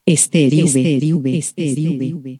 SteriUVfemECHO.mp3